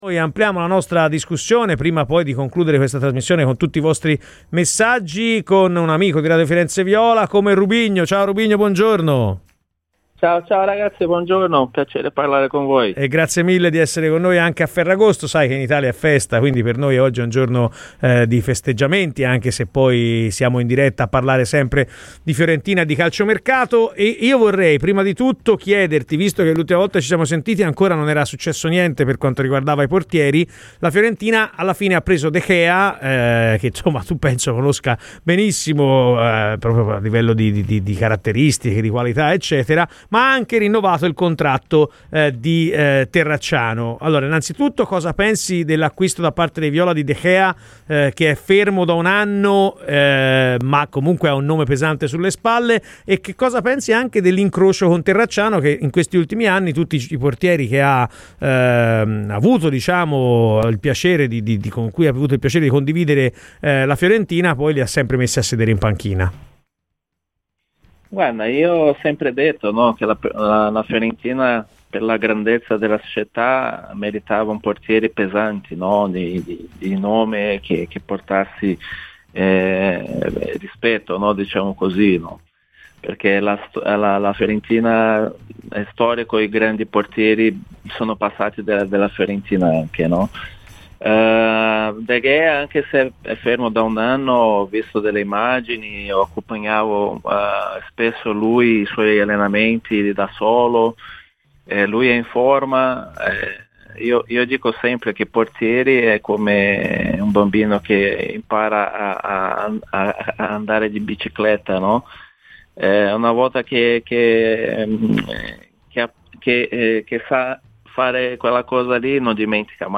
Radio FirenzeViola
PER L'INTERVENTO COMPLETO ASCOLTA IL PODCAST!